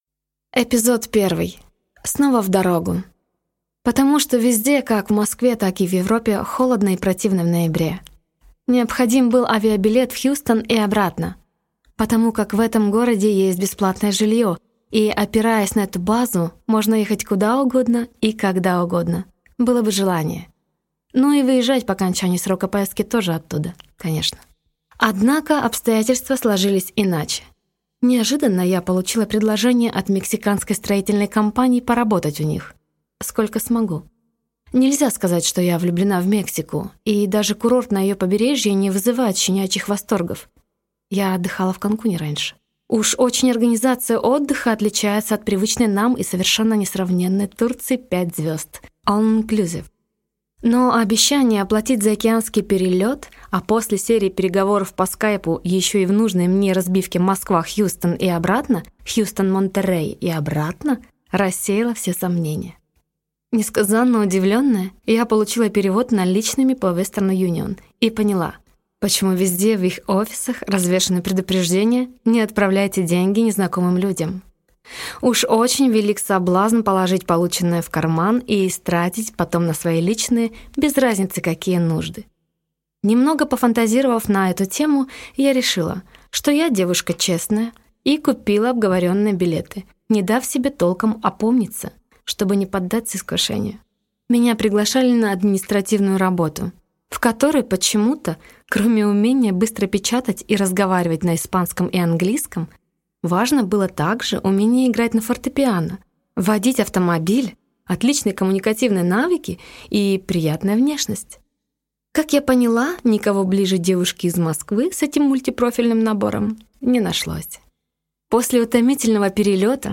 Аудиокнига Блондинка в Мексике | Библиотека аудиокниг